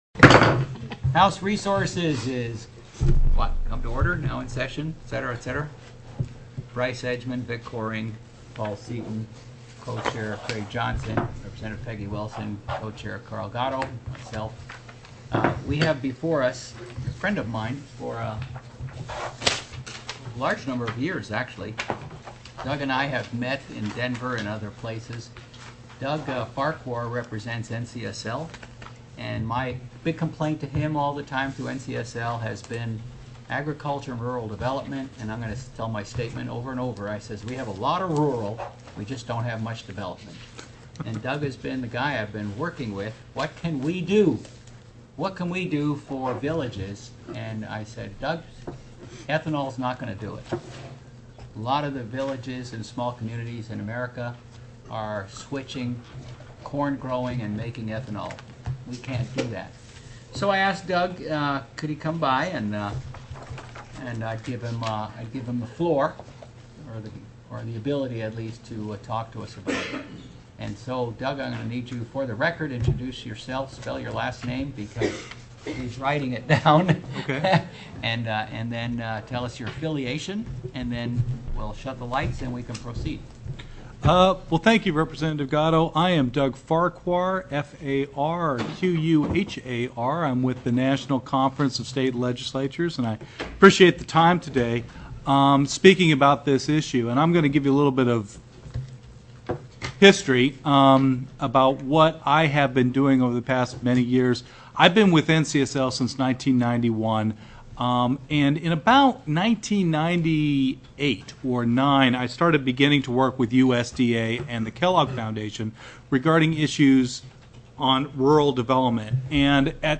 03/21/2007 01:00 PM House RESOURCES
TELECONFERENCED